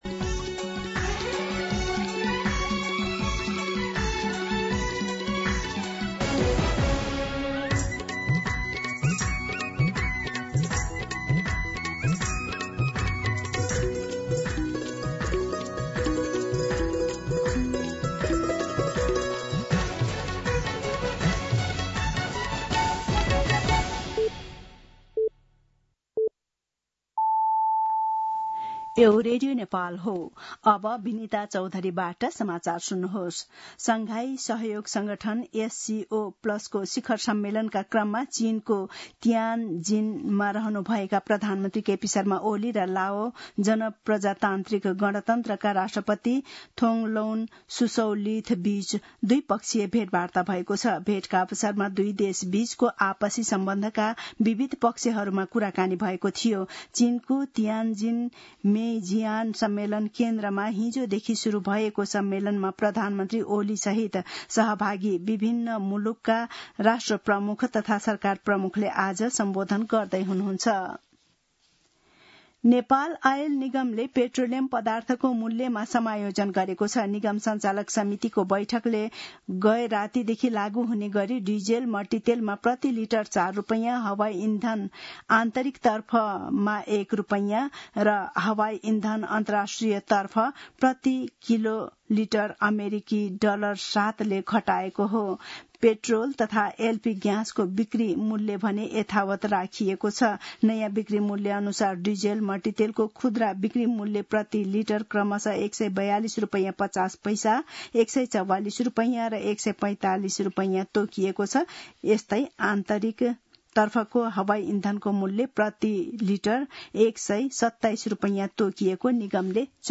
मध्यान्ह १२ बजेको नेपाली समाचार : १६ भदौ , २०८२
12pm-News-.mp3